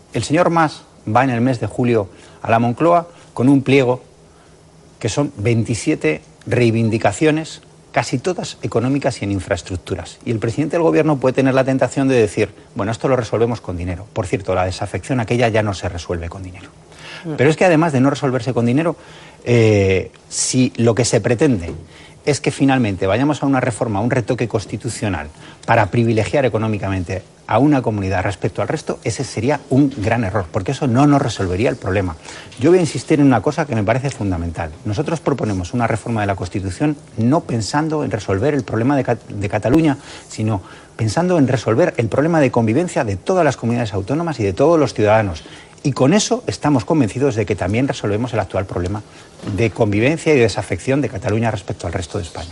Fragmento de la entrevista de Antonio Hernando en Canal Sur TV el 17/10/2014